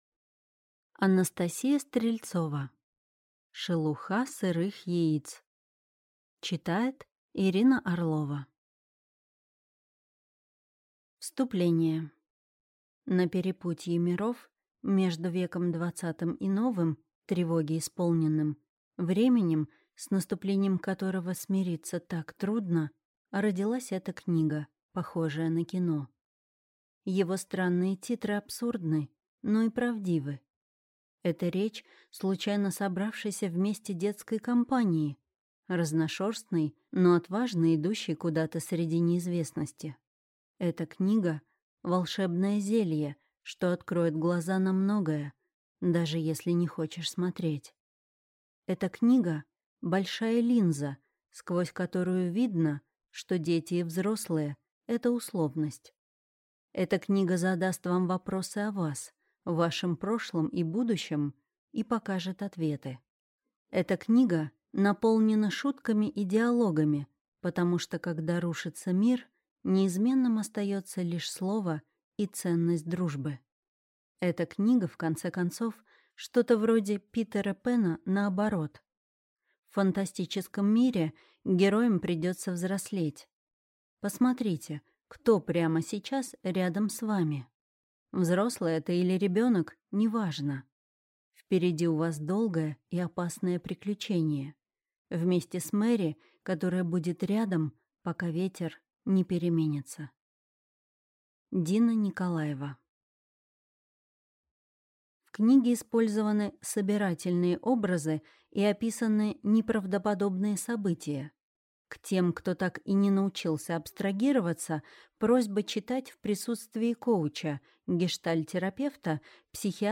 Аудиокнига Шелуха сырых яиц | Библиотека аудиокниг